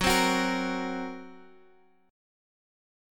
GbMb5 chord